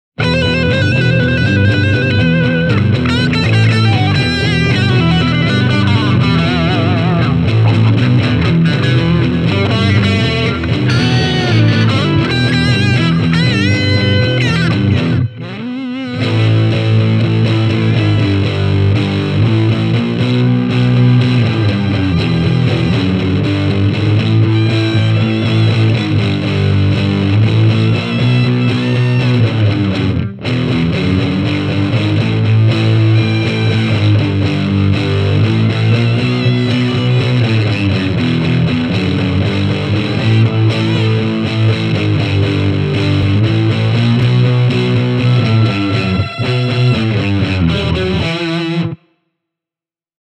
I used a Bluetone Shadows Jr. combo and a Shure SM57 to record all guitar tracks.
• rhythm guitars – Hamer USA Studio Custom (left channel), Gibson Melody Maker SG (centre), Fender Stratocaster (right channel)
• reverse guitar – Gibson Melody Maker SG
• lead guitar – Hamer USA Studio Custom, Morley wah-wah